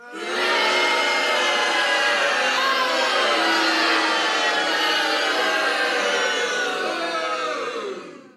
08 sfx Audience BOO
Tags: comedy